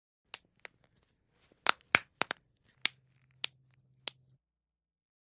IMPACTS & CRASHES - FIGHTS KNUCKLES: INT: Cracking, modest.